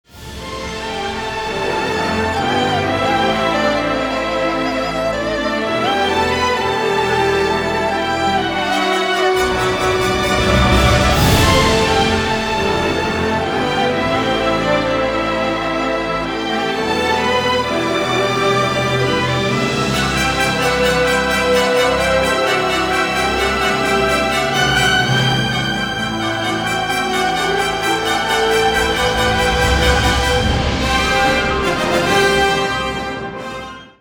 Музыка из игры
инструментальные, без слов, романтичные, оркестр